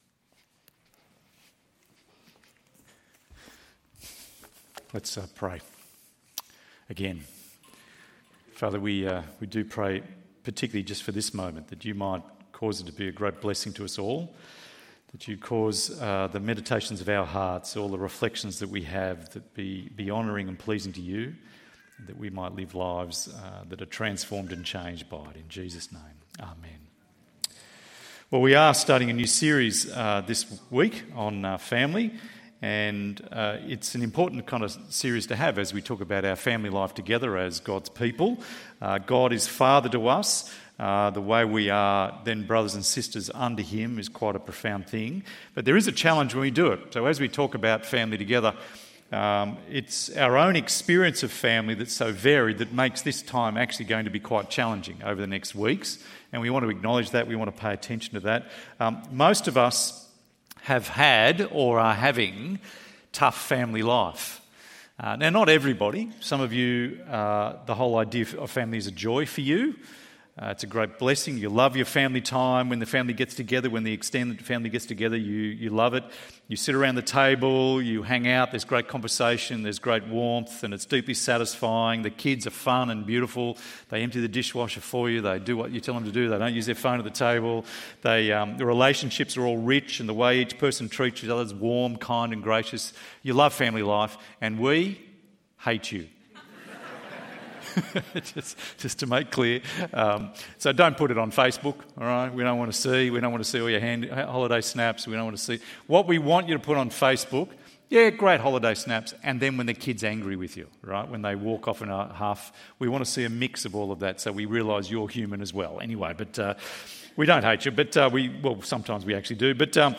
The Family of Grace ~ EV Church Sermons Podcast